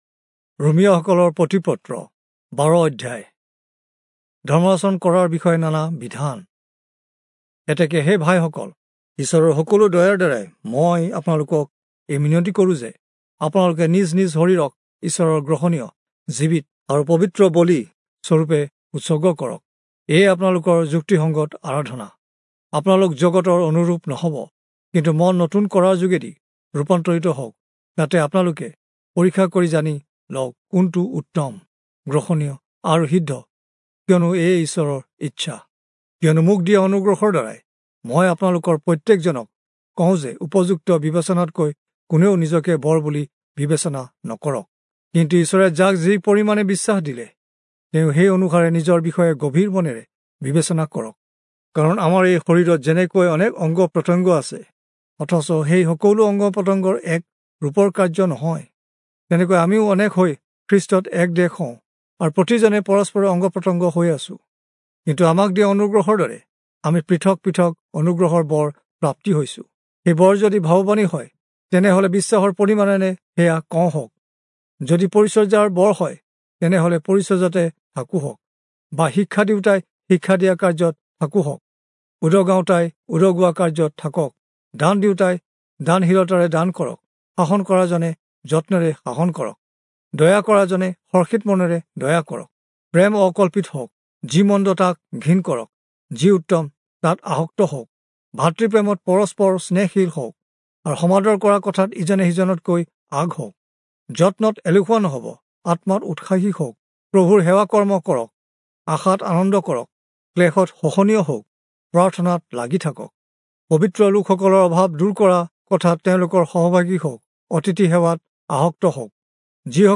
Assamese Audio Bible - Romans 6 in Ervkn bible version